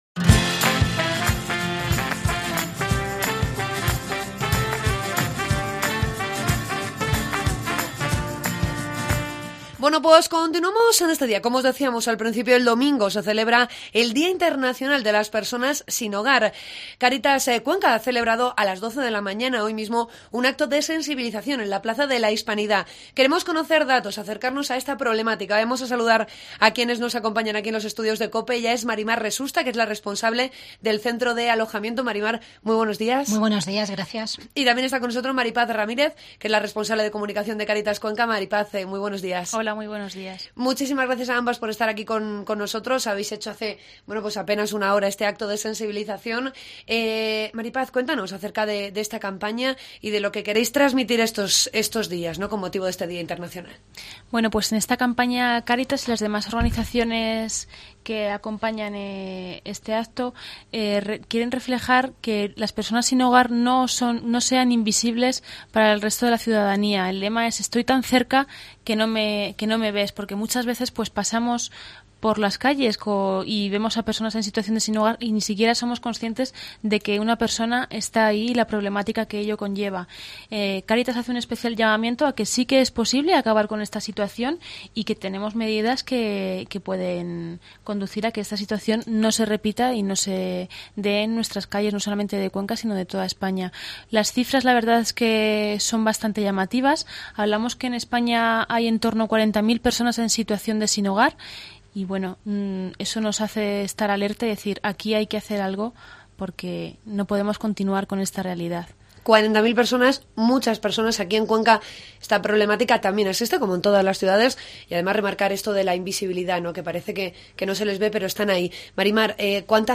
Entrevista con Cáritas Cuenca